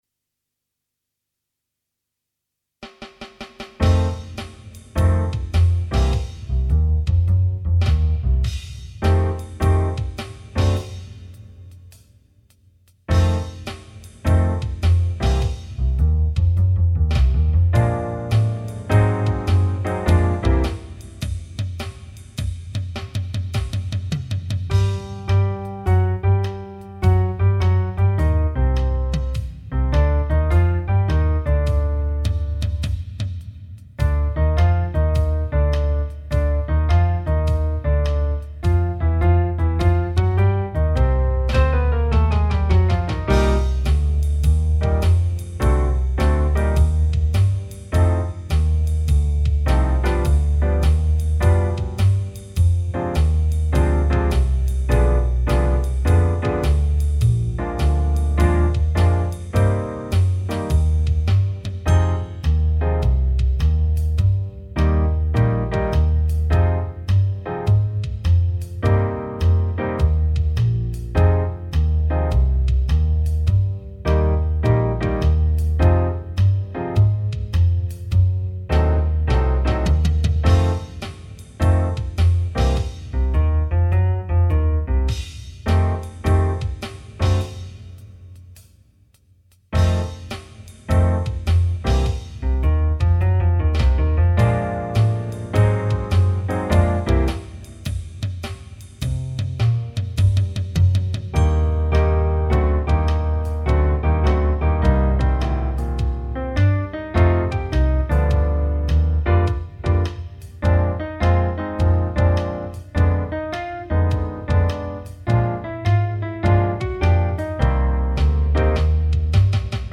minus Lead Instruments